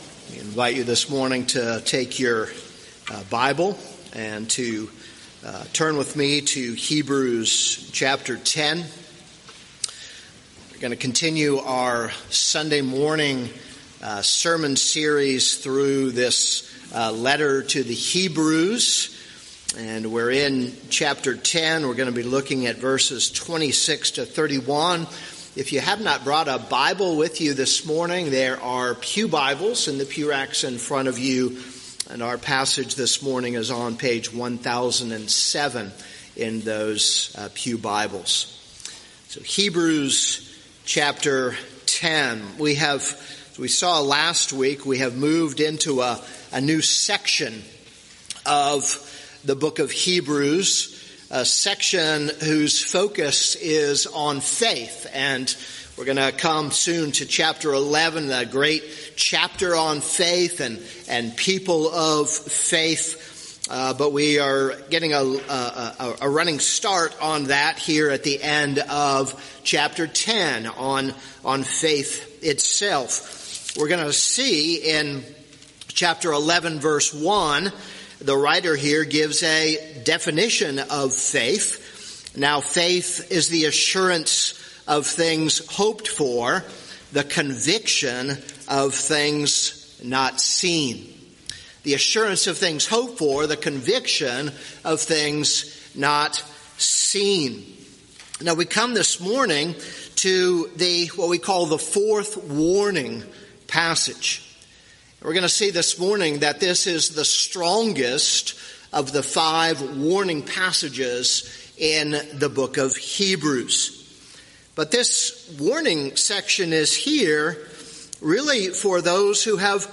This is a sermon on Hebrews 10:26-31.